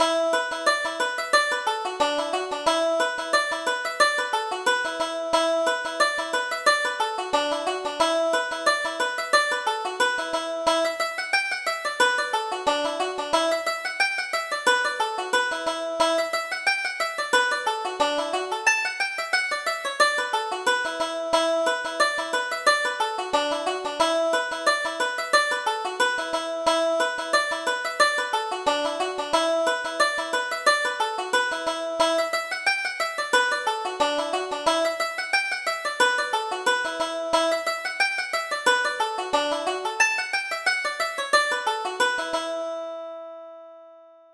Reel: The Youngest Daughter